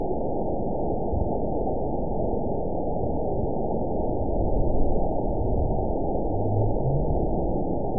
event 912224 date 03/21/22 time 12:20:44 GMT (3 years, 1 month ago) score 8.53 location TSS-AB04 detected by nrw target species NRW annotations +NRW Spectrogram: Frequency (kHz) vs. Time (s) audio not available .wav